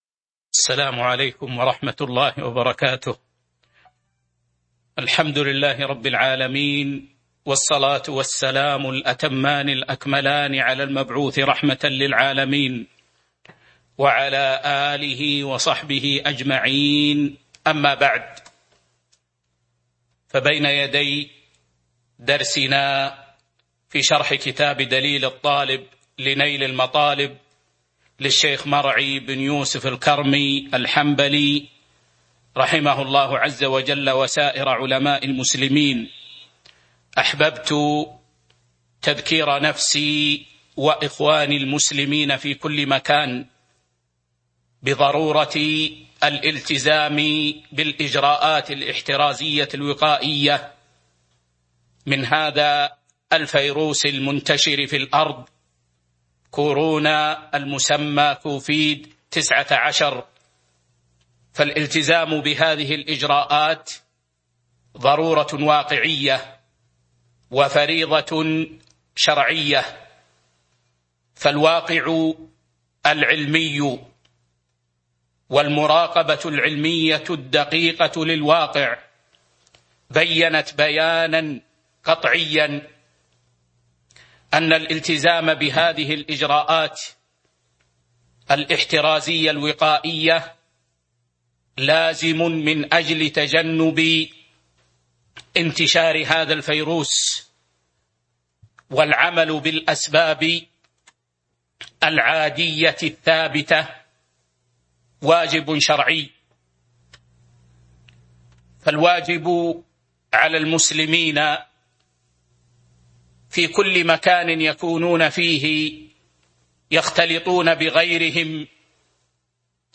تاريخ النشر ٢٩ جمادى الآخرة ١٤٤٢ هـ المكان: المسجد النبوي الشيخ